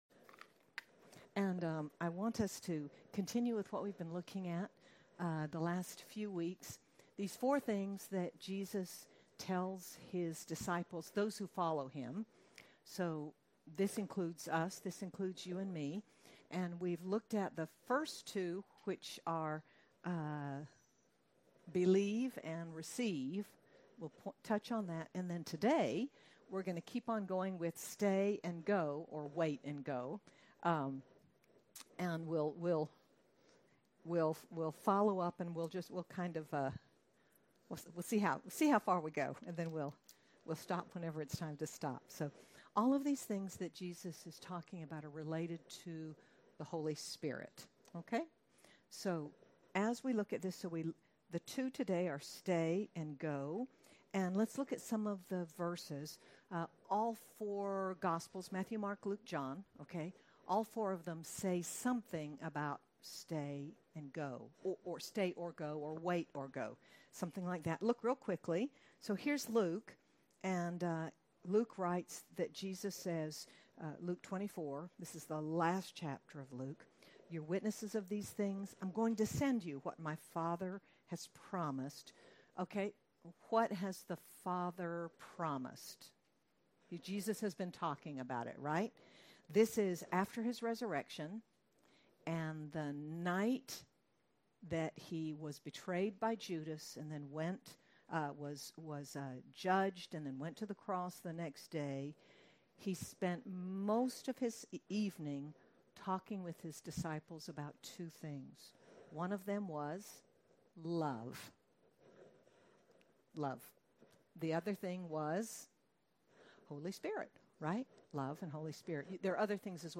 Jesus’ final commands remind us of the priority of the Holy Spirit in our lives. Sermon by